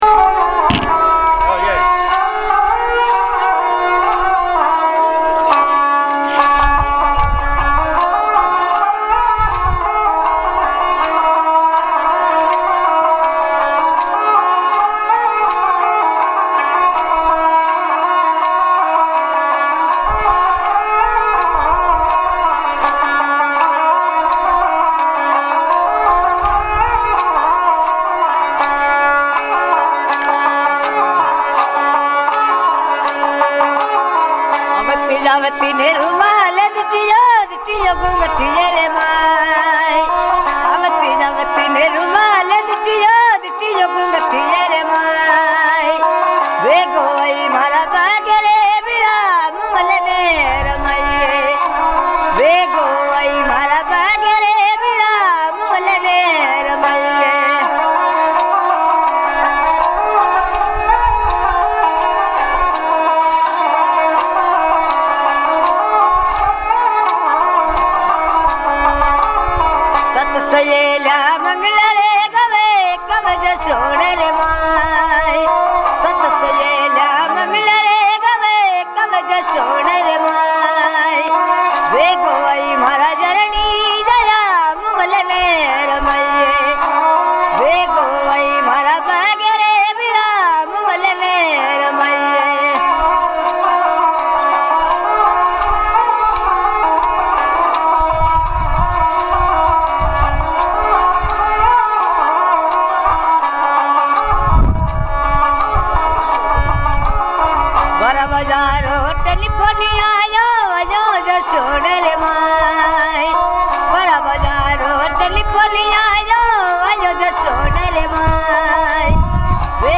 Die blaue Stadt Jodhpur ist eine Stadt wie aus einem Maerchen.
In einem der vielen Innenhoefe treffen wir auf einen Musiker, der ein traditionelles, entfernt an eine Geige erinnerndes Instrument spielt.
Rajasthani Music 1